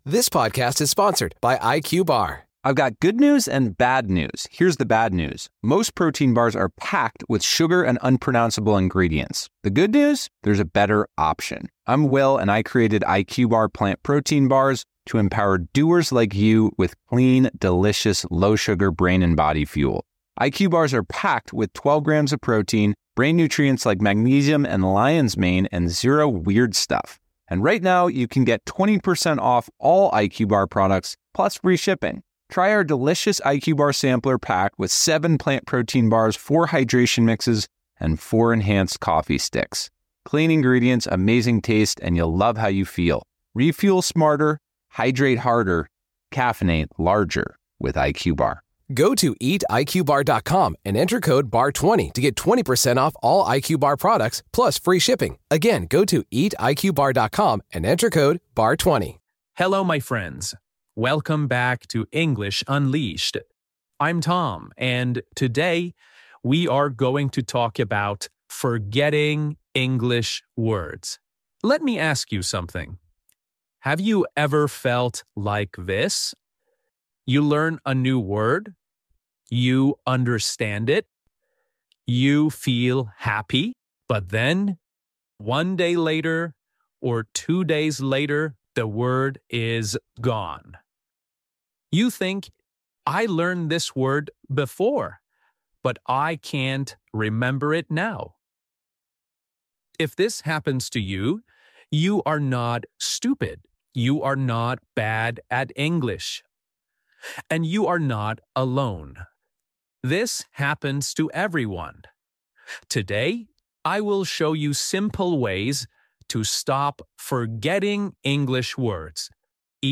This lesson is slow, clear, and easy to follow, even if you are just starting to learn English.
This lesson uses slow English, long simple sentences, and real examples to help your brain remember words naturally.